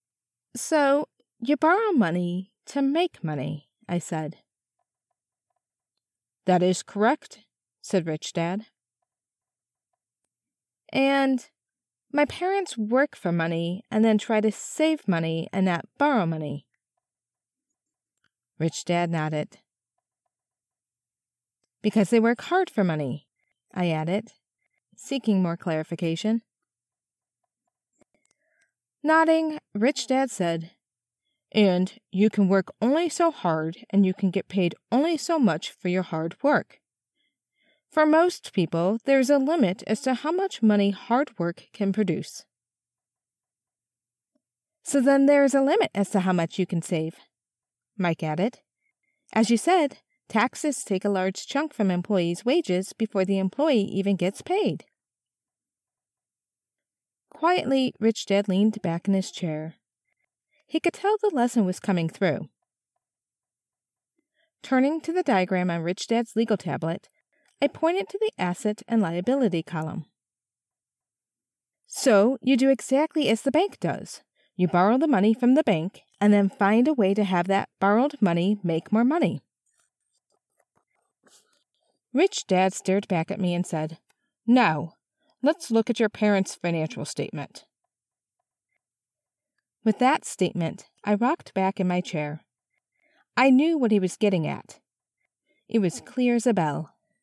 Home Studio Specs: AT2020USB+ Cardioid Condenser USB Mic, Audacity, DropBox or WeTransfer.
Personal Finance | 3x M | Calm, Clear, Confident
Warm, Grounded, Midwest accent
Clear and intuitive